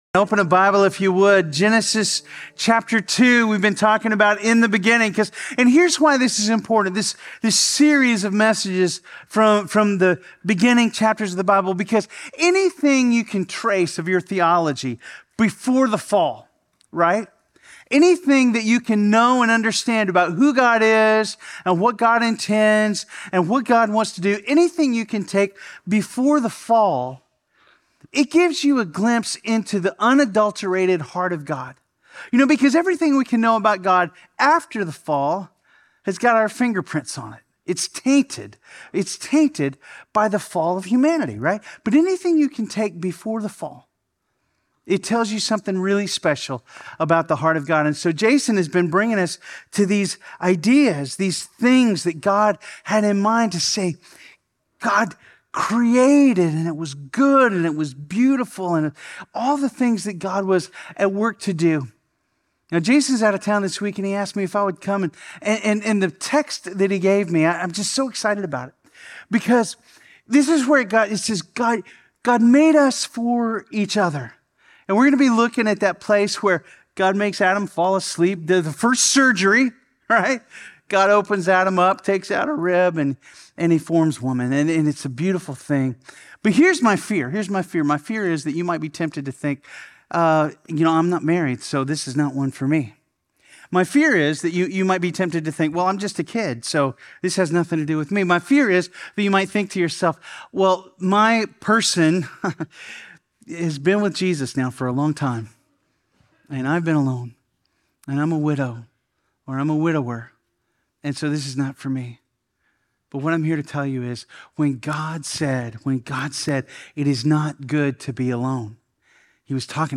Sermons | FBC Platte City